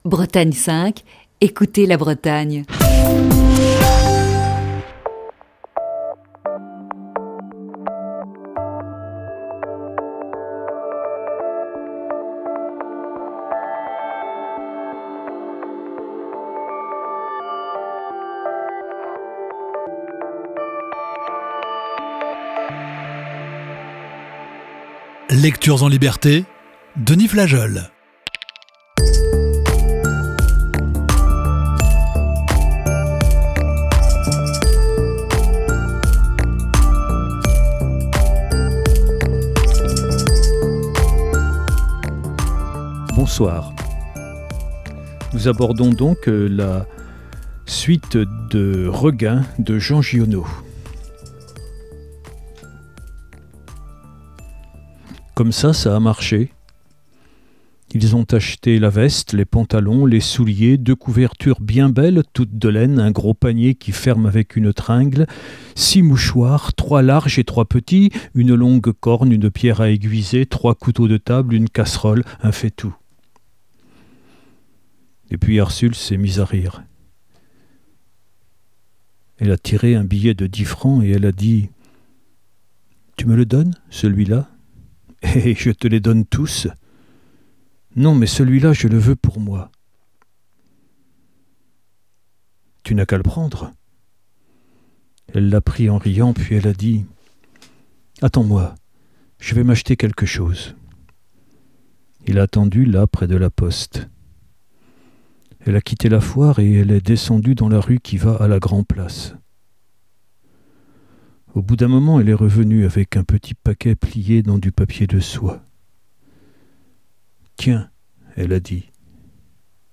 Émission du 17 décembre 2020.